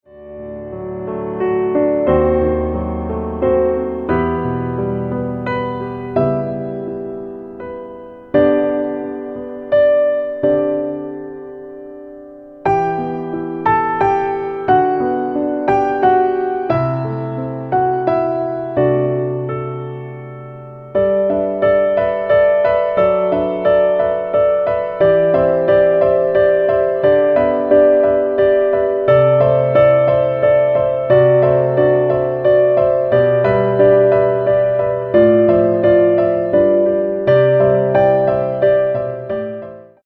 Piano - Low